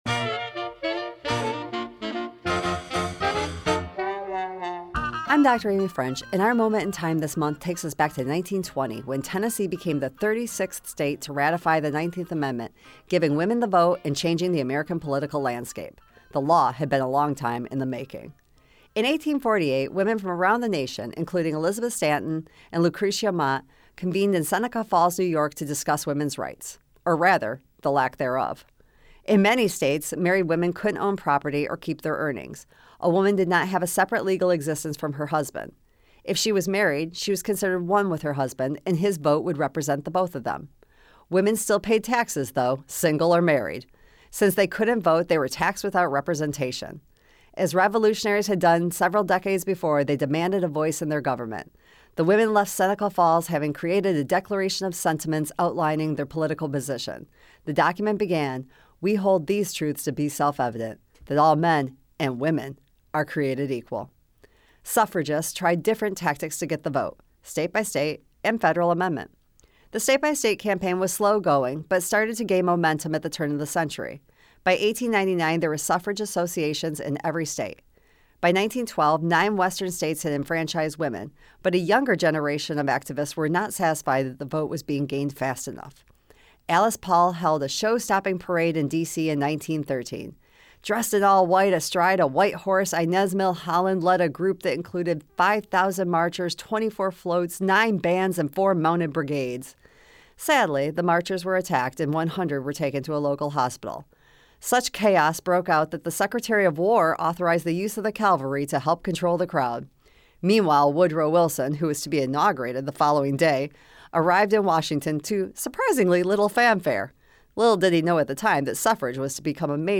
Ph.D. originally aired on FMQ 90.1 for Delta College’s public radio program, Moments in Time.